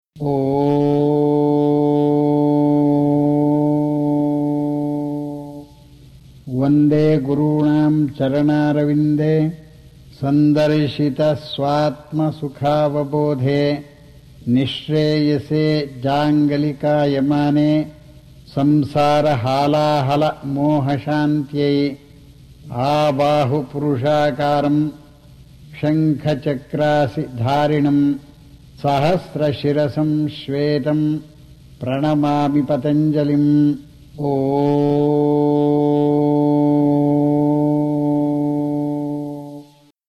Guruji recitando el Ashtanga Yoga Mantram
Guruji_chanting_the_Opening_Mantra.mp3